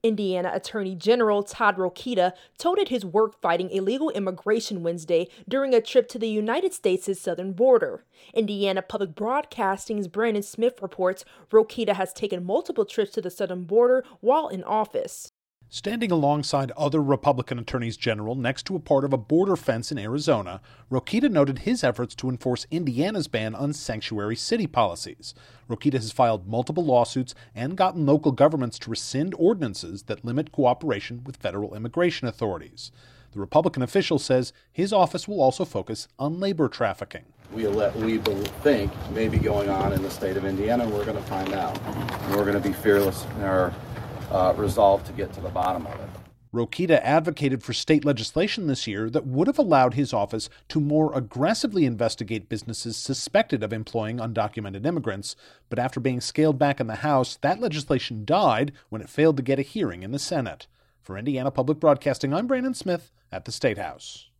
Standing alongside other Republican attorneys general next to a part of a border fence in Arizona, Rokita noted his efforts to enforce Indiana’s ban on sanctuary city policies. Rokita has filed multiple lawsuits and gotten local governments to rescind ordinances that limit cooperation with federal immigration authorities.
rokita-with-ags-at-southern-border.mp3